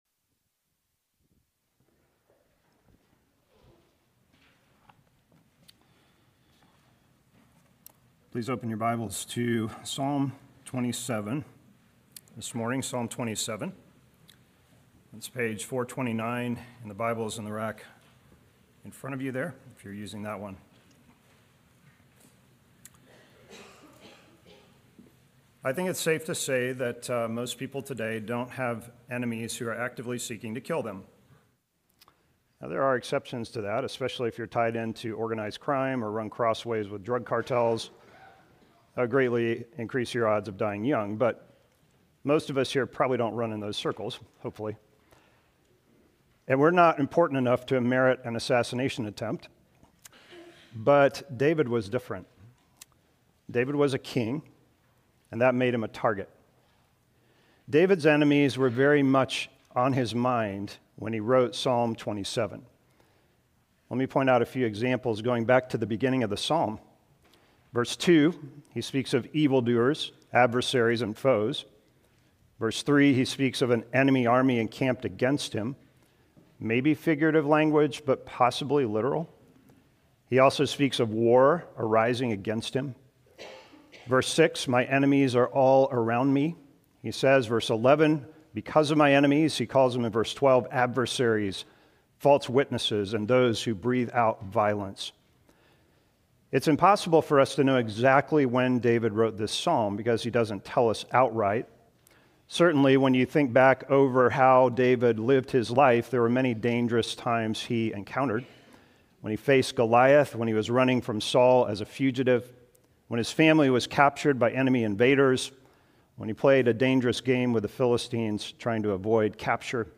Sermons – Bethany Baptist Church Brevard, NC